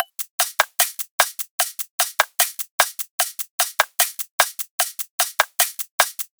VR_top_loop_straightforward_150.wav